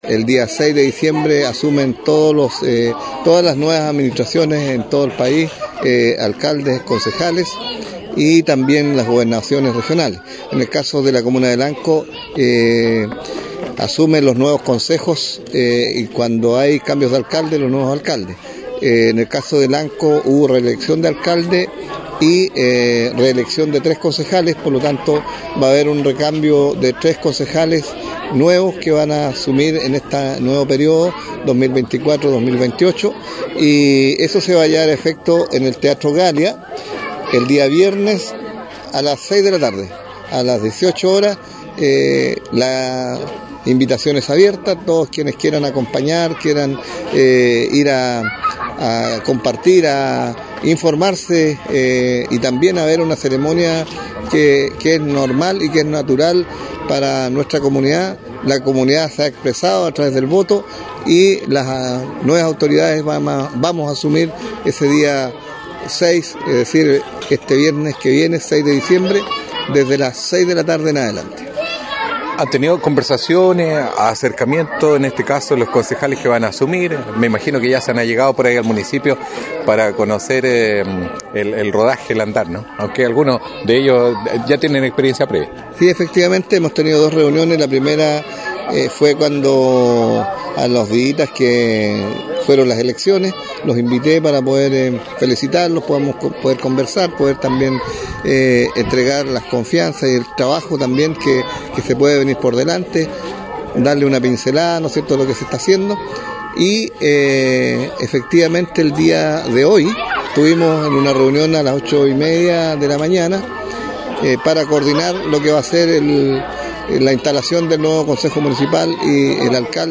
En conversación con Portal Radio, el alcalde Juan Rocha destacó la relevancia de este evento, que representa el inicio de un nuevo ciclo en la administración municipal.